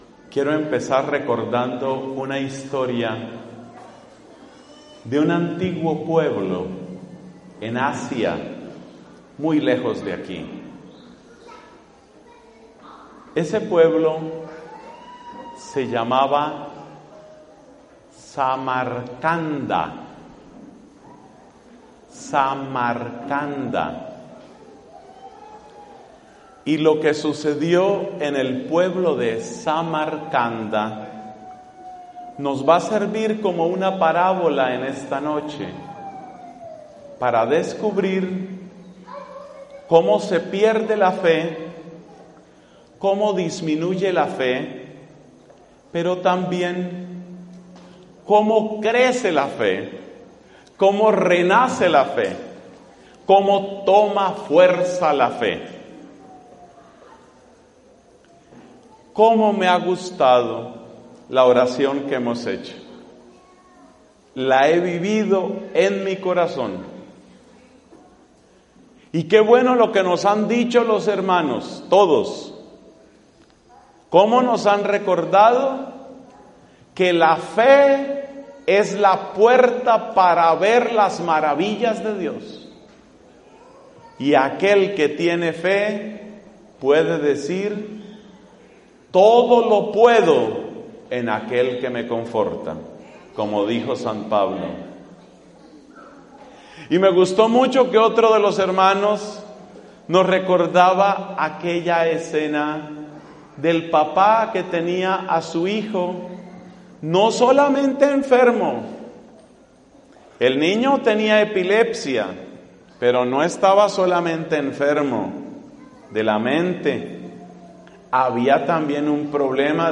[Predicación en la parroquia de San Pedro y San Pablo, de San José Villanueva, Guatemala, en Abril de 2013.]